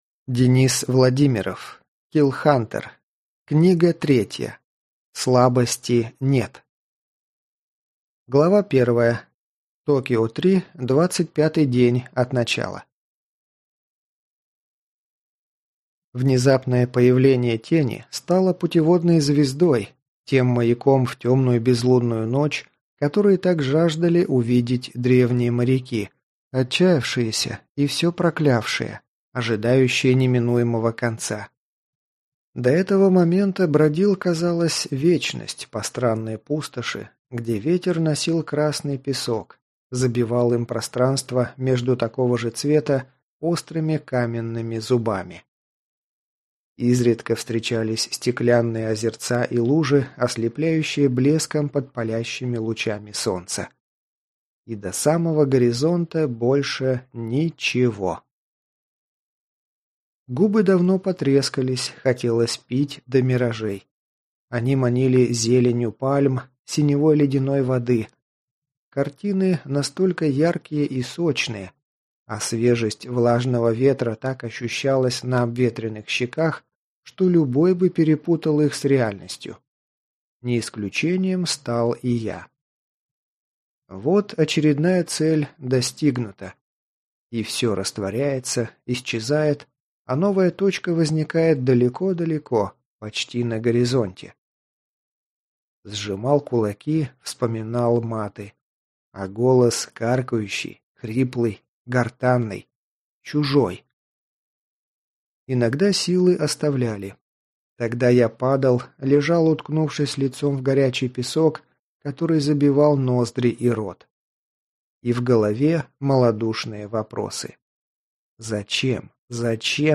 Аудиокнига Слабости нет!